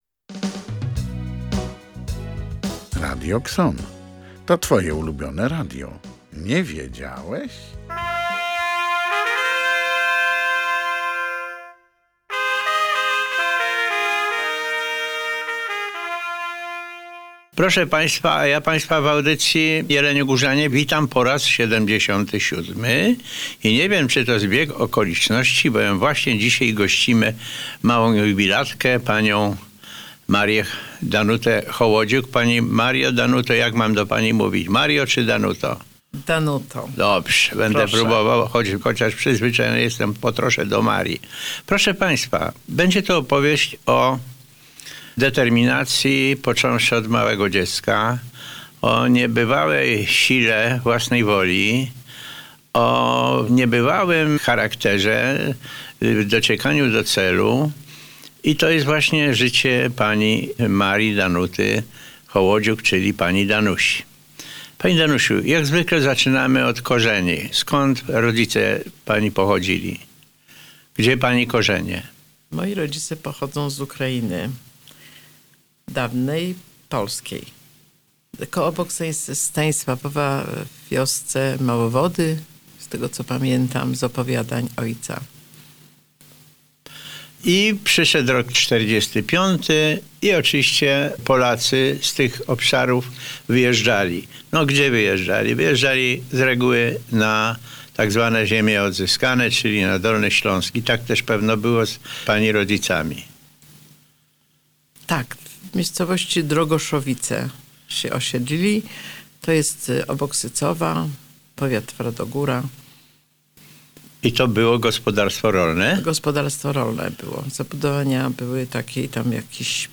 Radio KSON prezentuje wywiad